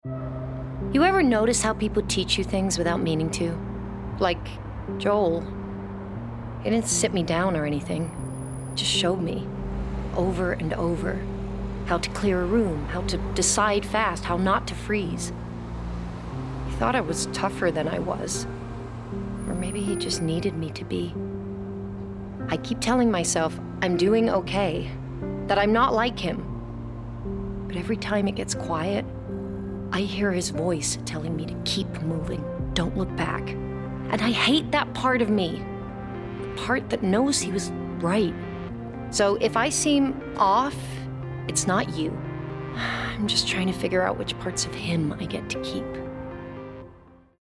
20/30's Neutral/RP,
Husky/Natural/Engaging
Flawed Hero (American) Young Protagonist (London)